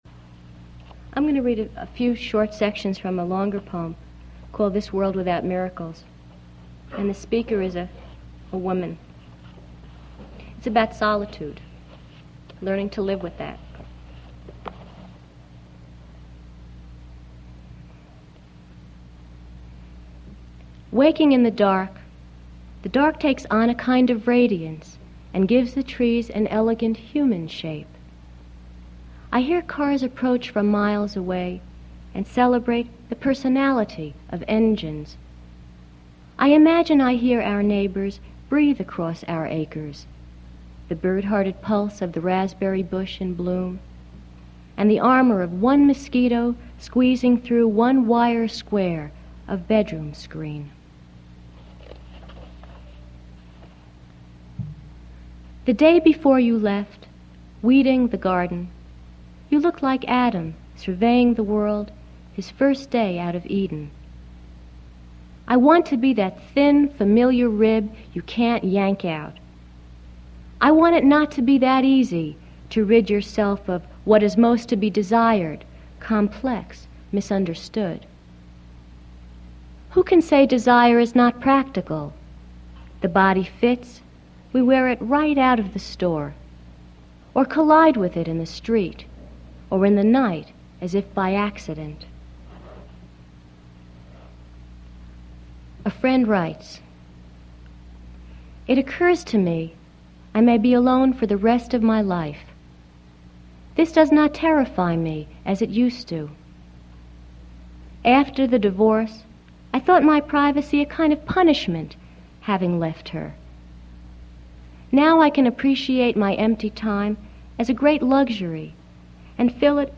Jane Shore reads poems appearing in Eye Level (1977) and The Minute Hand (1987).